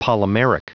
Prononciation du mot polymeric en anglais (fichier audio)
Prononciation du mot : polymeric